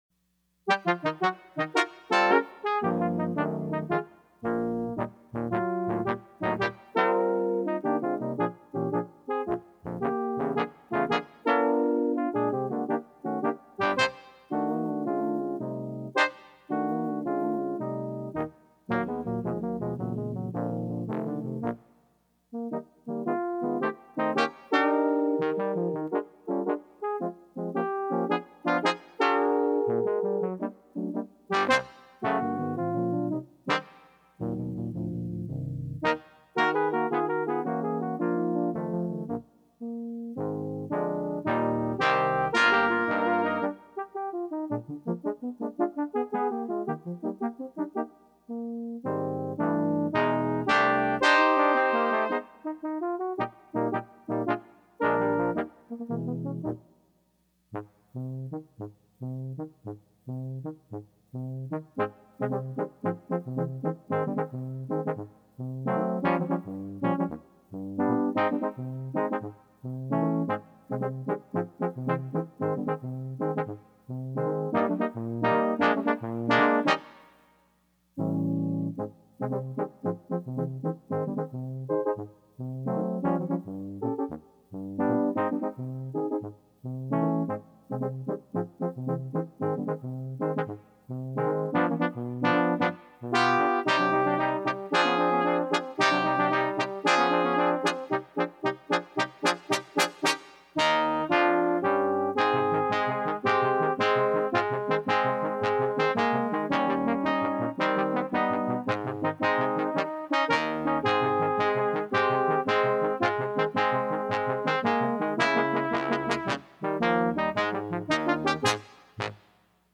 in a jazz style!
jazz